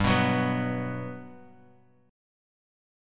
GM7 chord